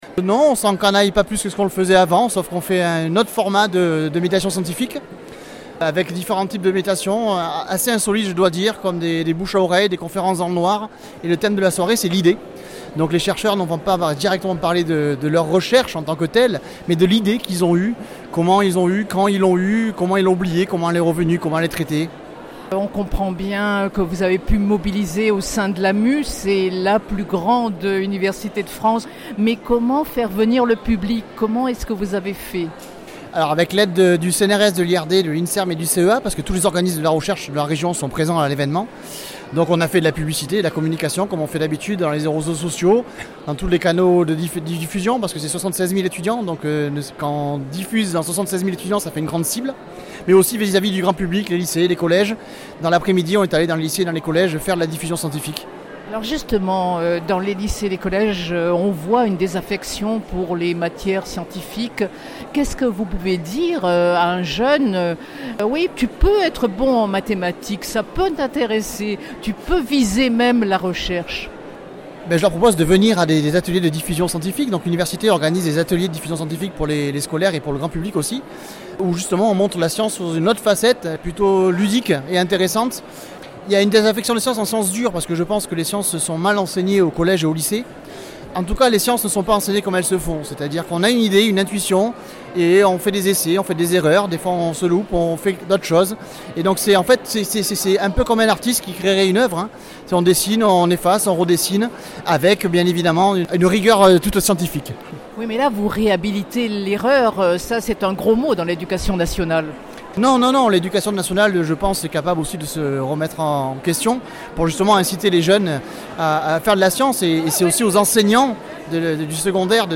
Nous sommes à l’entrée de la Nuit Européenne des Chercheurs 2016, organisée par Aix Marseille Université (AMU).